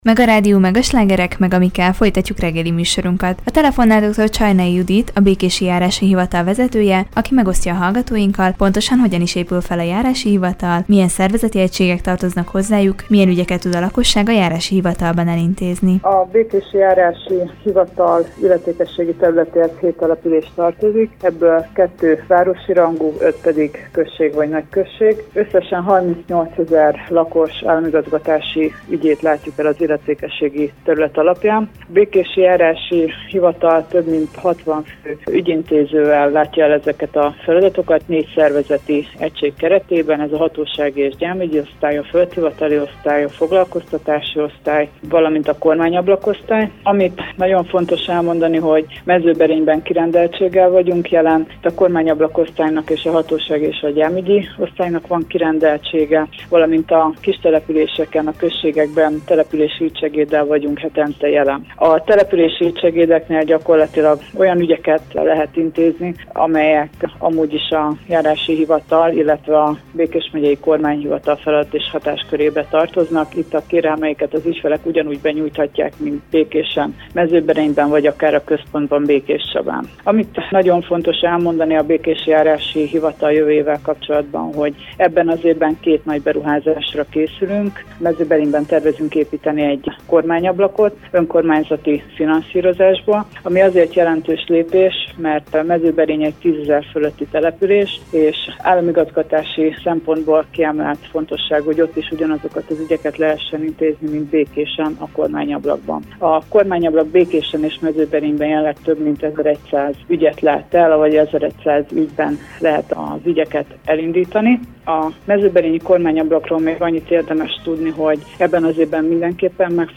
A Békési Járási Hivatal vezetőjével, Dr. Csarnai Judittal beszélgetett tudósítónk a hivatal szervezeti felépítéséről, az illetékességi területről valamint az ott folyó munkákról.